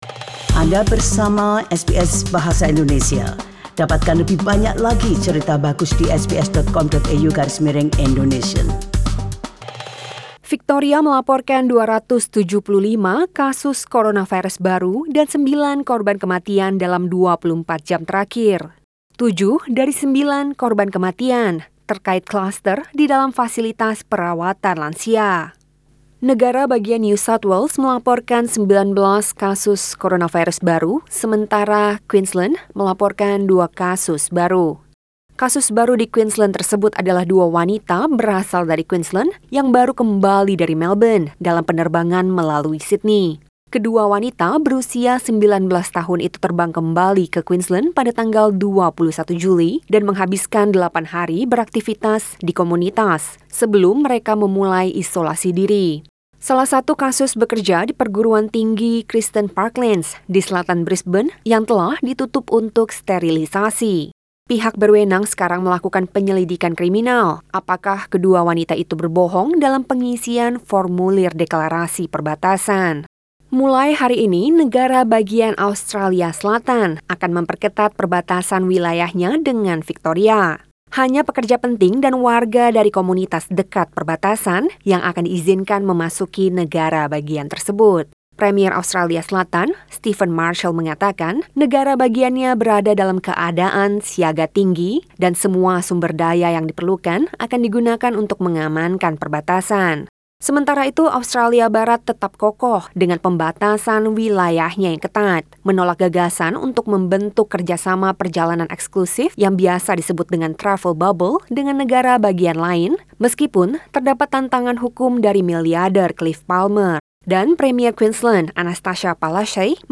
SBS Radio News in Bahasa Indonesia - 29 July 2020